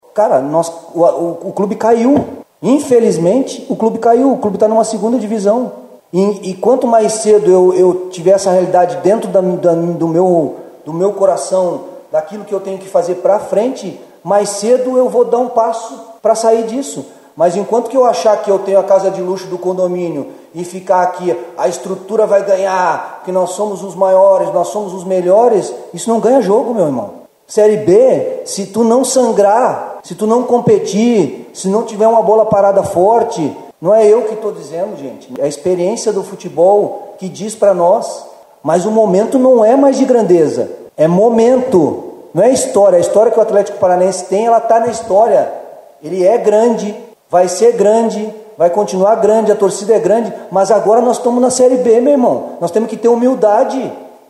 Hellmann disse que na competição é preciso “sangrar” e não achar que tem a “casa de luxo do condomínio”. Ouça a declaração feita pelo treinador depois do jogo de sábado (24) na Ligga Arena: